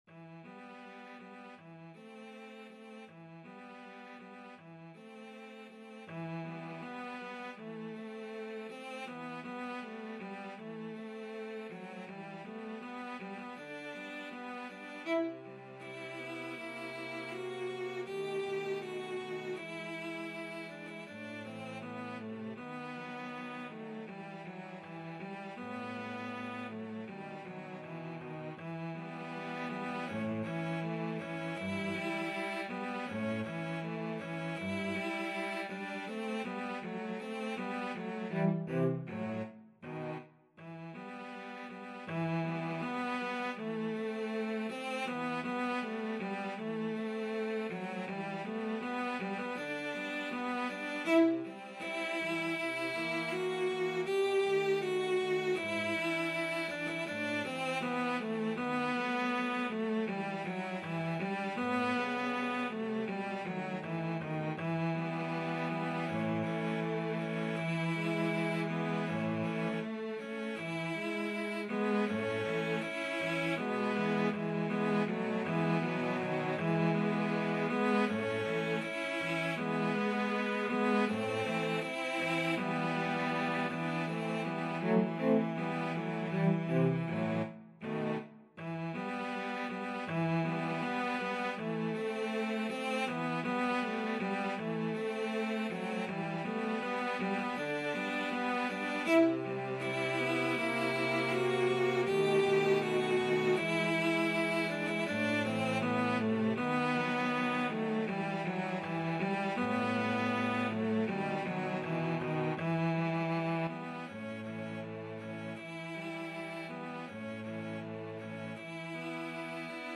Free Sheet music for Cello Trio
2/4 (View more 2/4 Music)
E minor (Sounding Pitch) (View more E minor Music for Cello Trio )
~ = 100 Andante
Classical (View more Classical Cello Trio Music)